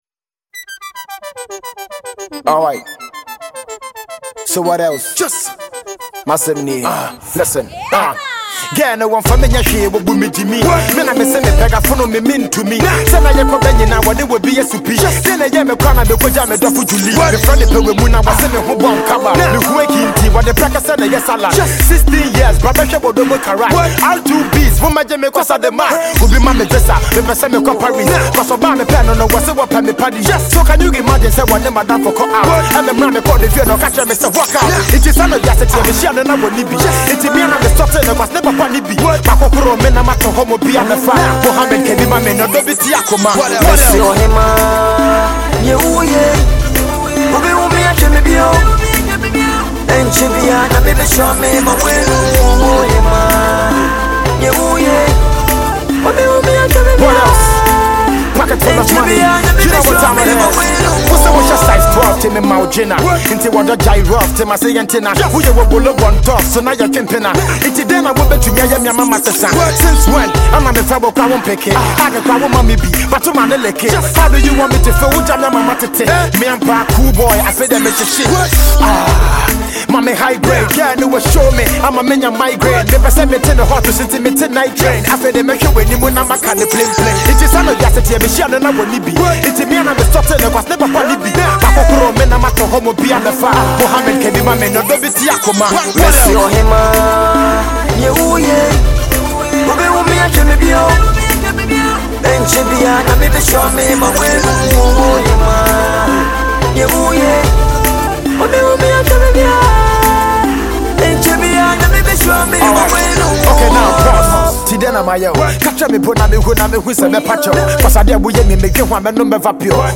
African indigenous Rap maestro
bass-heavy banger
he’s assisted by a sensational singer